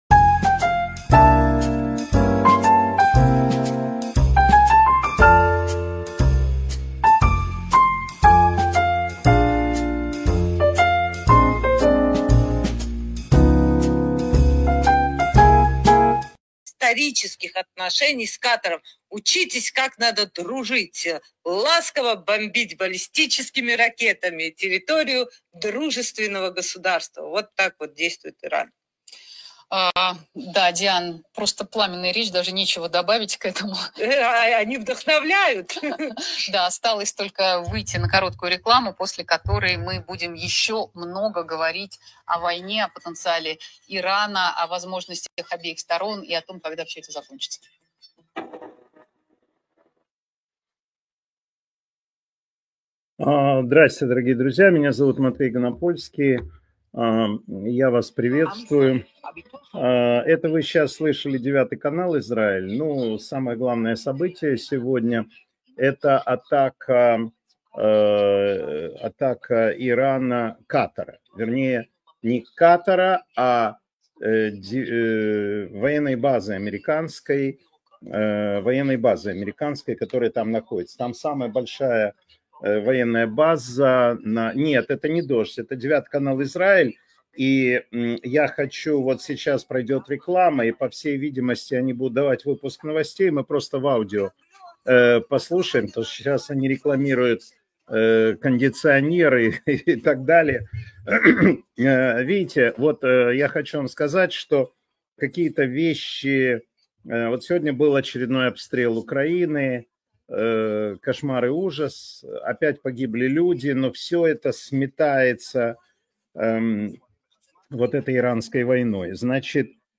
Эфир ведёт Матвей Ганапольский